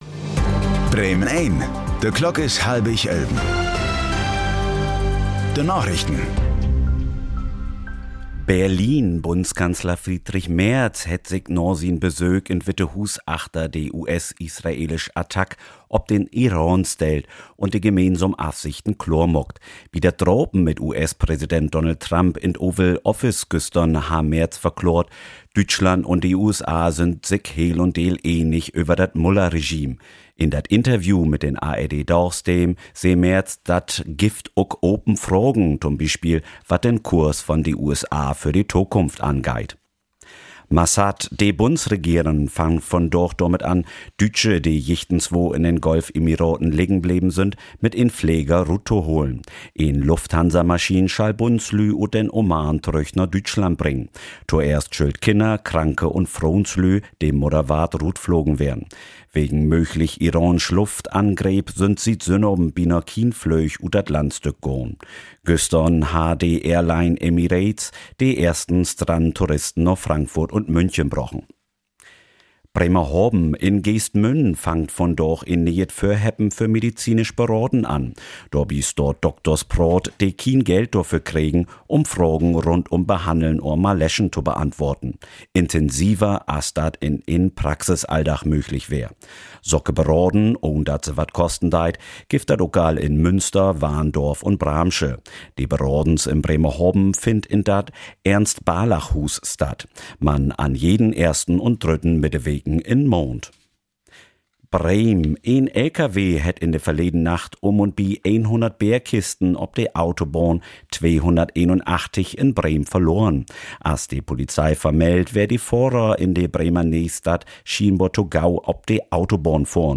Plattdüütsche Narichten vun'n 4. März 2026